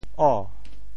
How to say the words 啊 in Teochew？